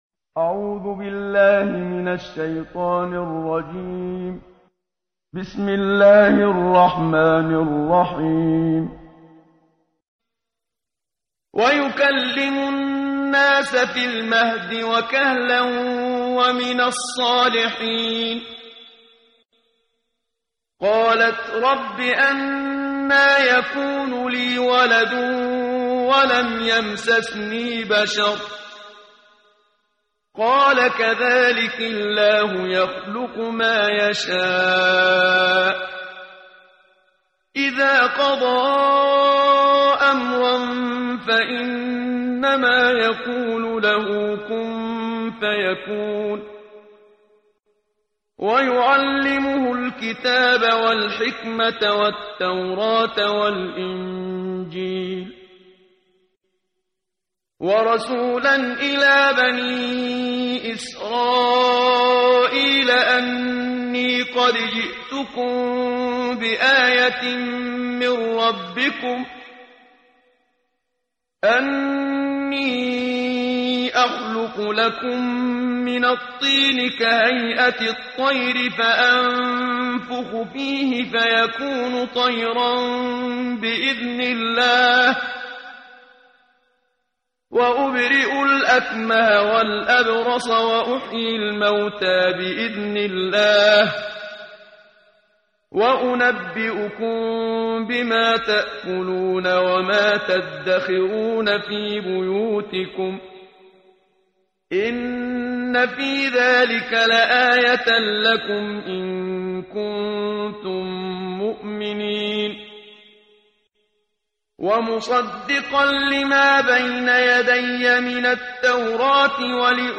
قرائت قرآن کریم ، صفحه 56، سوره مبارکه آلِ عِمرَان آیه 46 تا 52 با صدای استاد صدیق منشاوی.